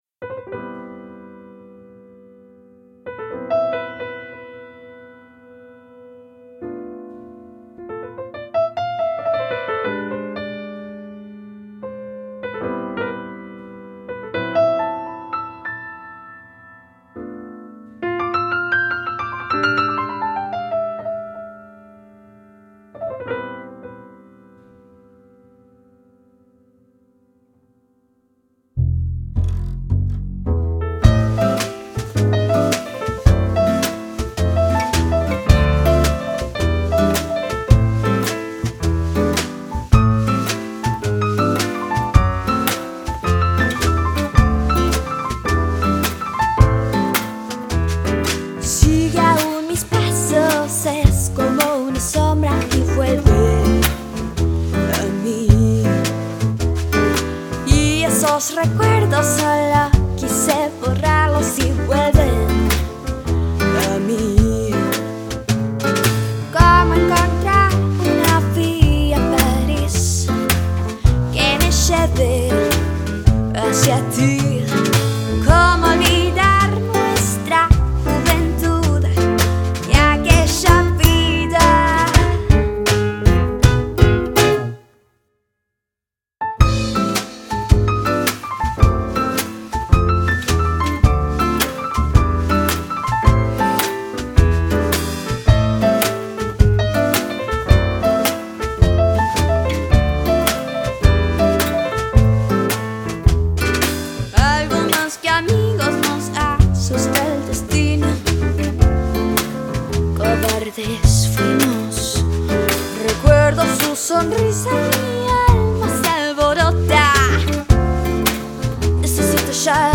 • Genre: Blues / Jazz / Swing
Gesang
Gitarre
Schlagzeug
Piano
Kontrabass